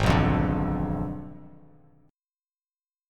G#dim7 chord